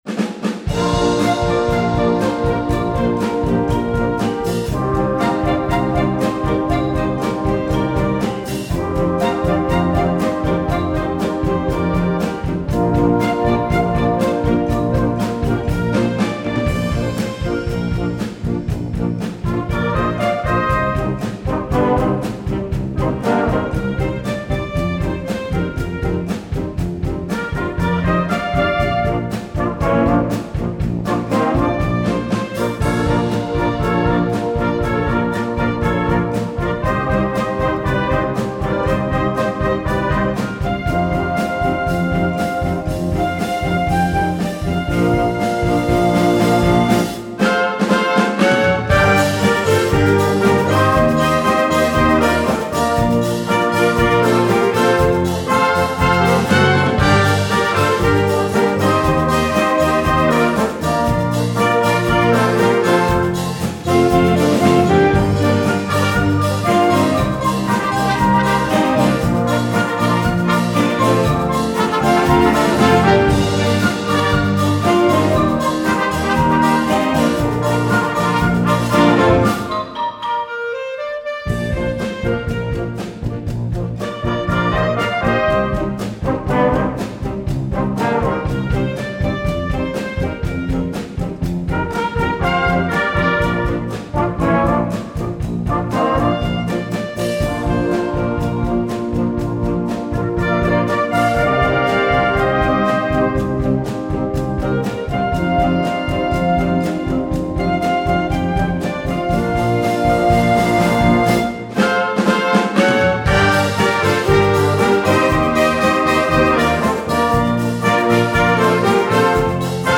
Gattung: Stimmungshit
Besetzung: Blasorchester
In Originaltonart.